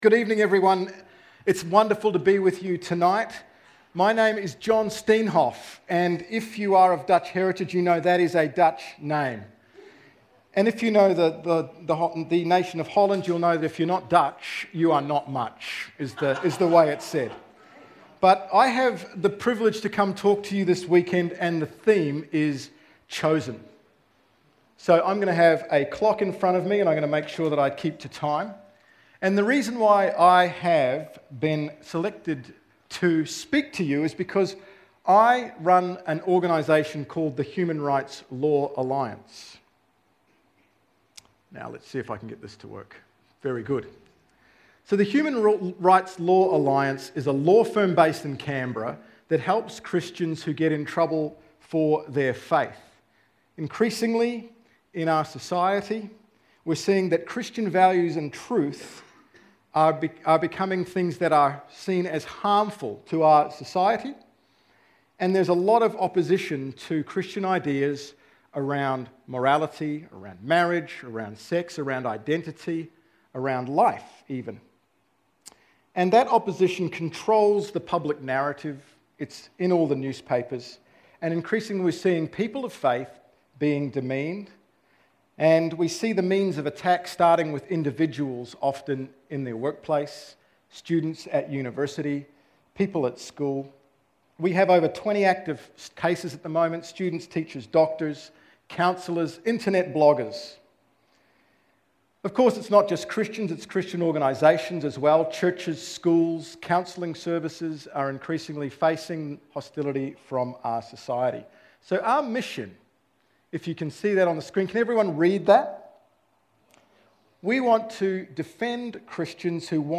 Our theme for YA Retreat 2024 was 'Chosen.'